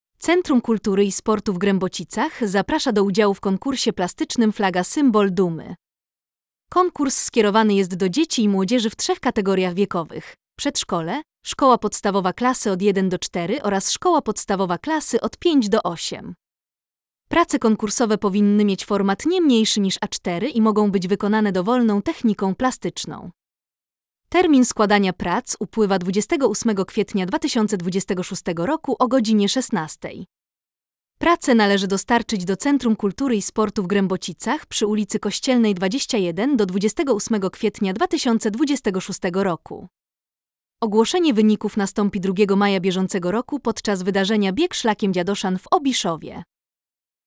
lektor.mp3